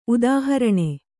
♪ udāharaṇe